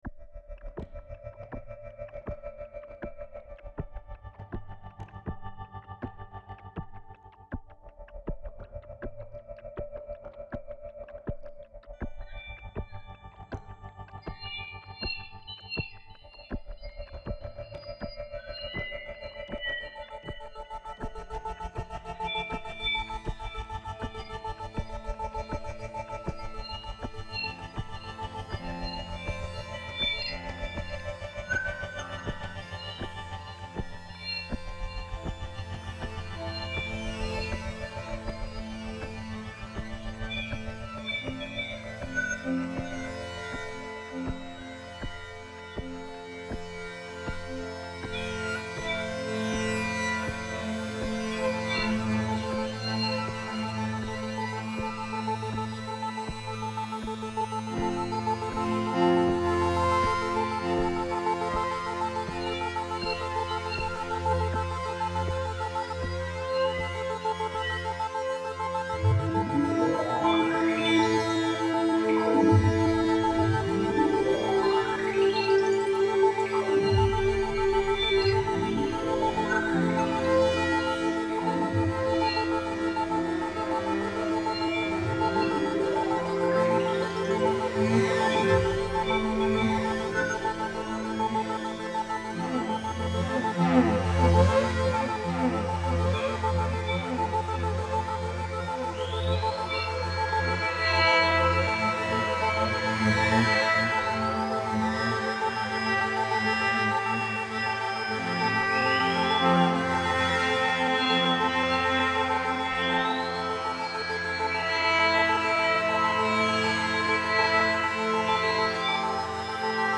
track ambient: smoking area
Ambient - Downtempo